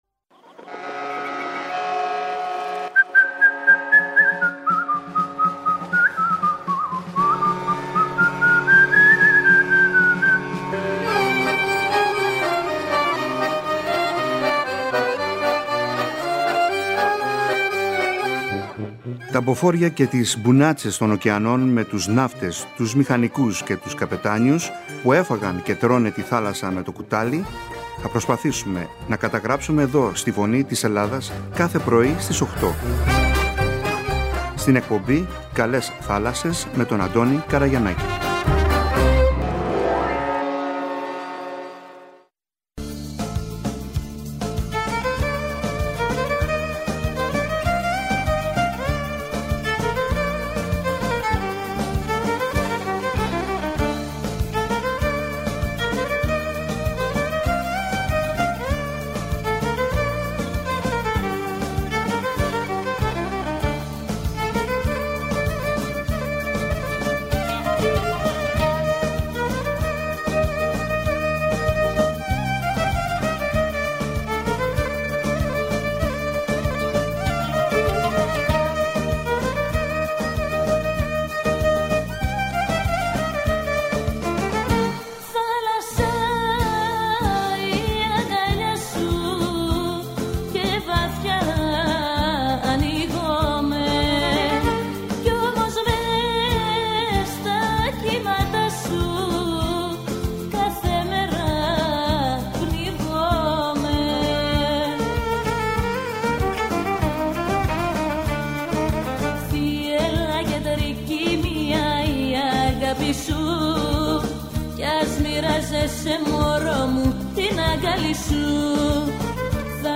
Ο Δήμαρχος της Σκύρου Κυριάκος Αντωνόπουλος στις «Καλές Θάλασσες» | 11.09.2024